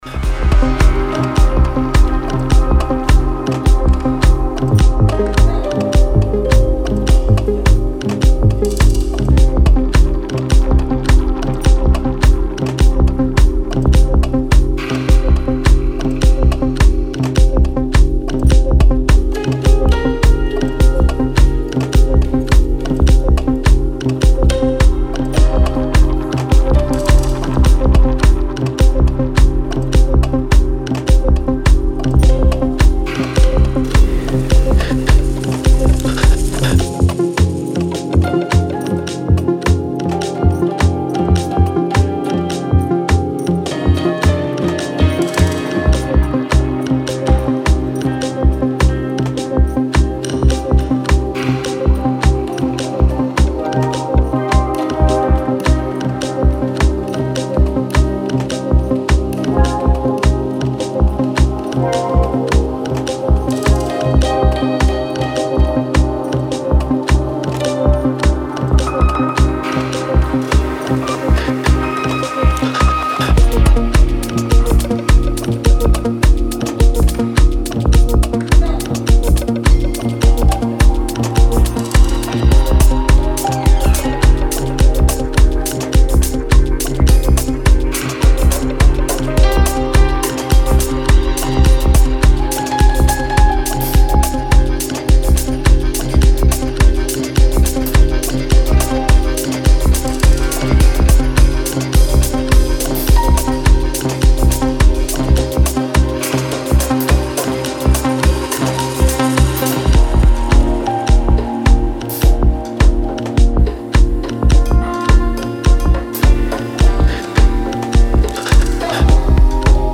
Style: Slow Neotrance / Slow House